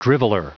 Prononciation du mot driveler en anglais (fichier audio)
Prononciation du mot : driveler